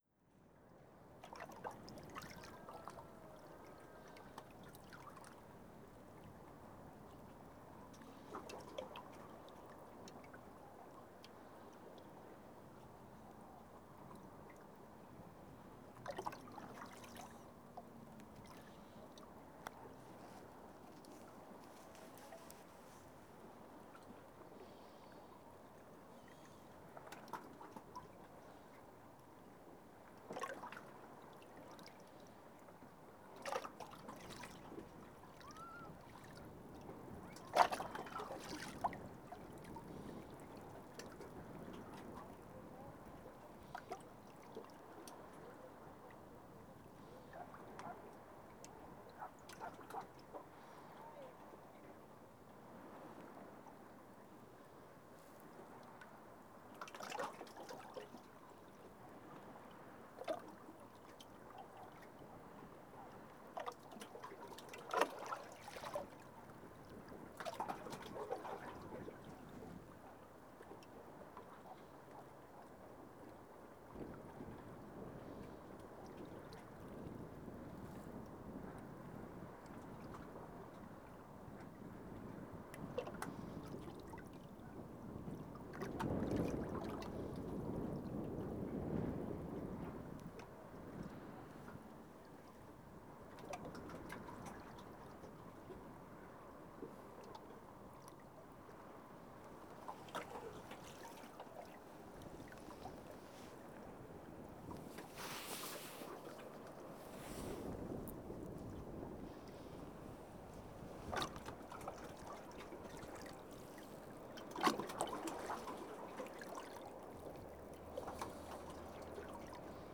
This is a compilation from the ongoing project ‘Situations and Circumstances’ as a series of field recordings that are made public in this release intending to be available for free download and potential reuse in new works of other artists.